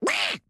Enemy Duck Notice Sound Button - Free Download & Play